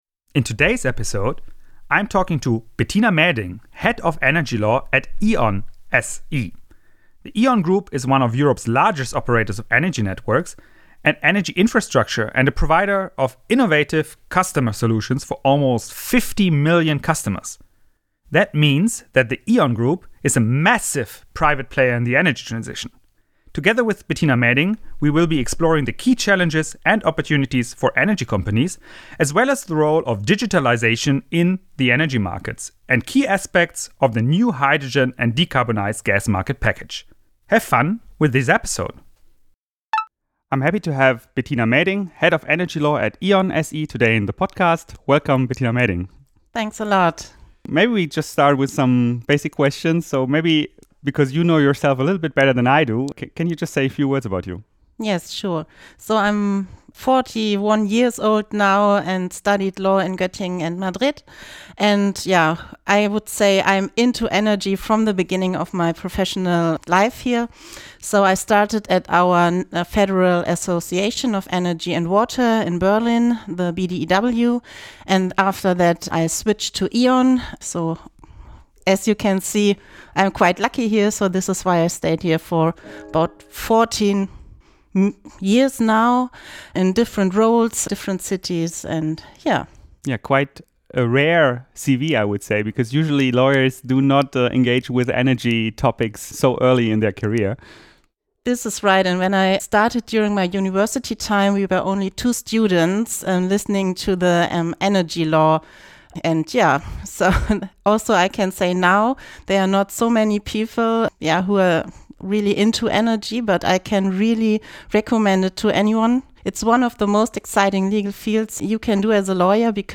The interview took place on 11 March 2024.